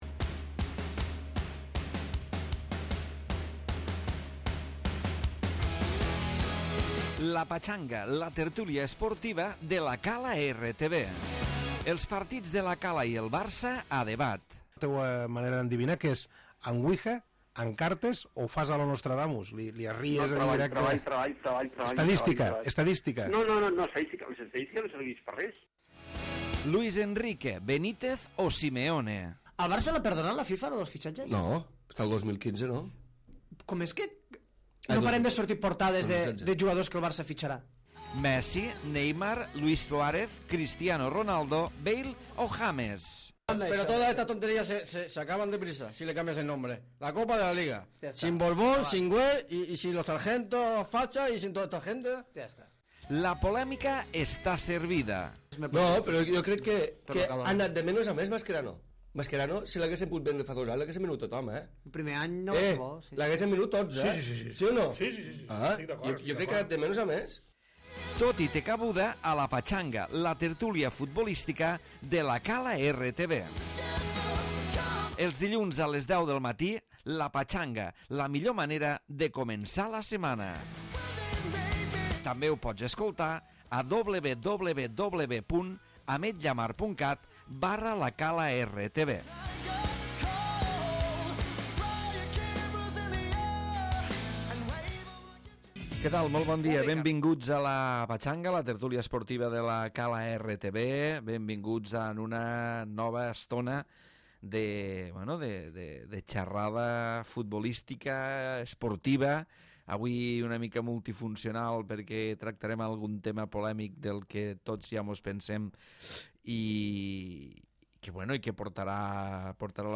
Tertúlia futbolística